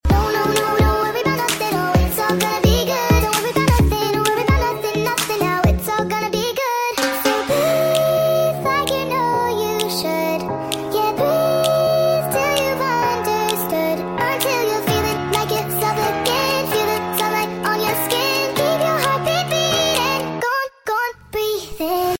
A warm, soft, honey-like voice resonating in the world